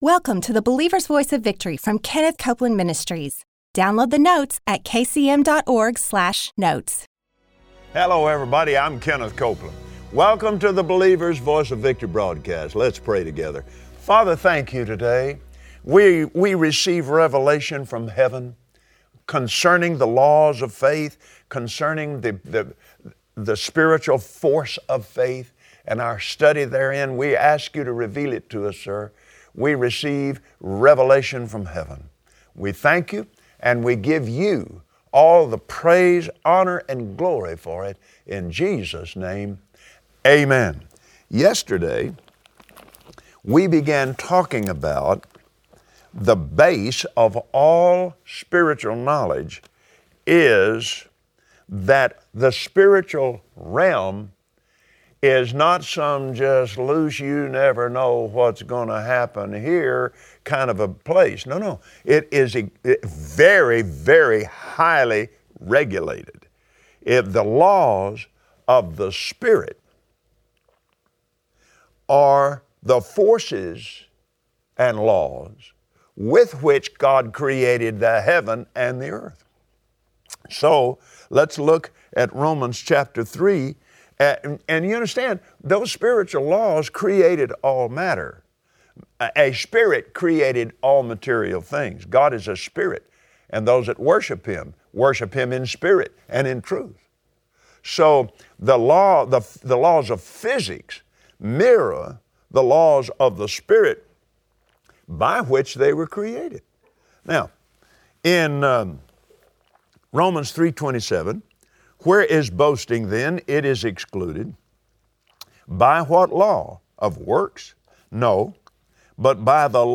Join Kenneth Copeland today, on the Believer’s Voice of Victory, as he shares about Jesus, the author and developer of our faith.